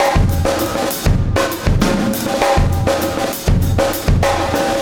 Extra Terrestrial Beat 21.wav